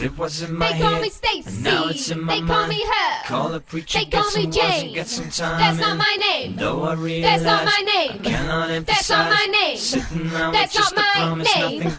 I have a clip, acapella, with both male and female vocals.
Listening to the clip it sounds as if the male vocals are double track and paned far left and far right.